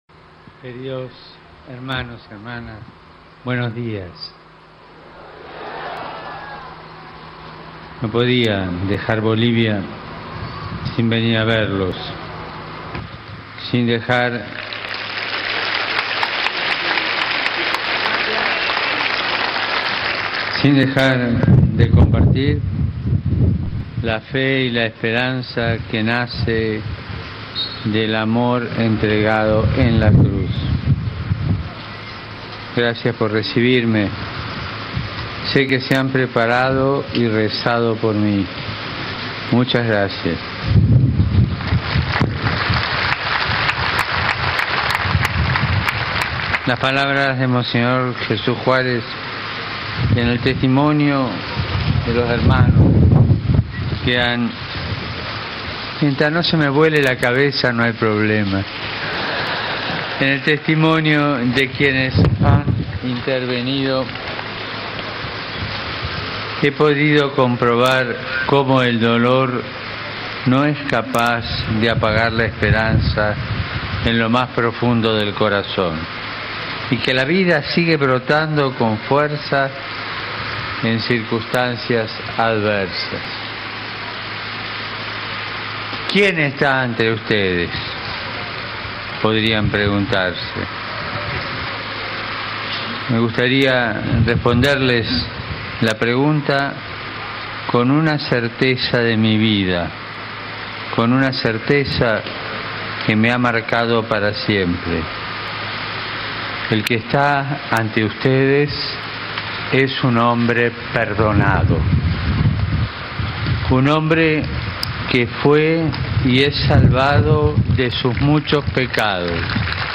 Discurso-del-papa-en-el-penal-de-Palmasola